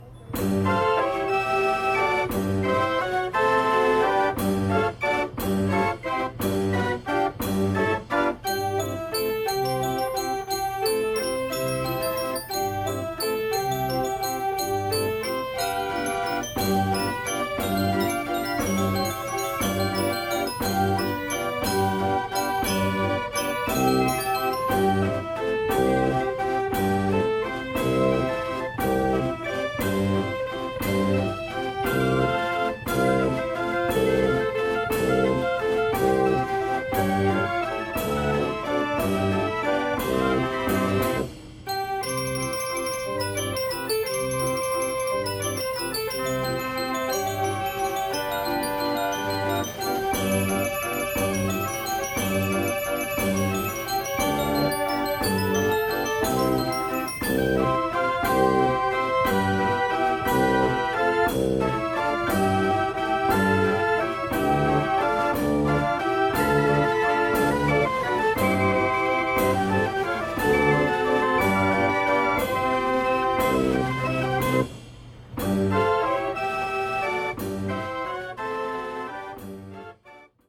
Waltz